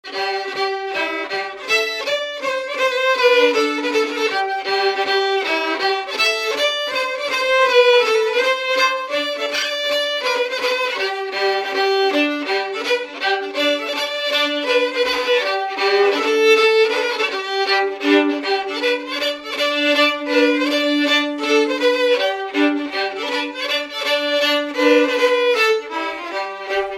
Résumé instrumental
danse : mazurka
Pièce musicale inédite